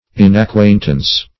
Inacquaintance \In`ac*quaint"ance\, a.